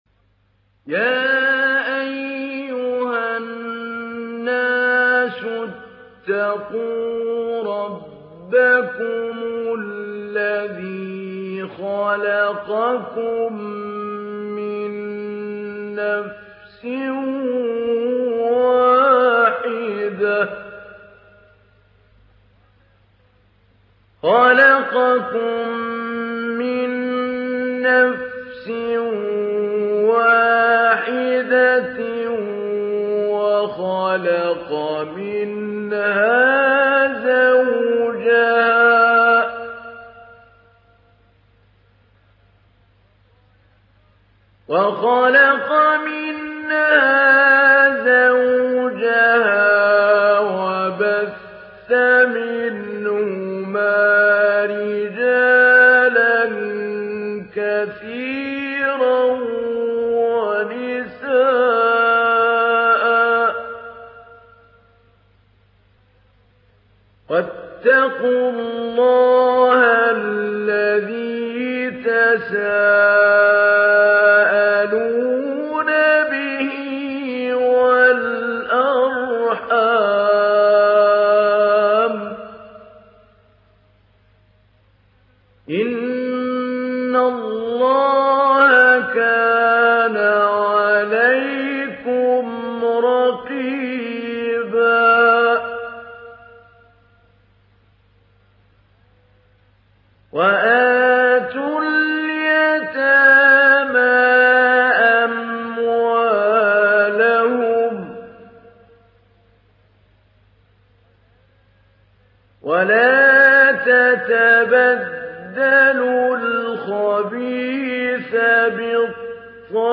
Sourate Annisa mp3 Télécharger Mahmoud Ali Albanna Mujawwad (Riwayat Hafs)
Télécharger Sourate Annisa Mahmoud Ali Albanna Mujawwad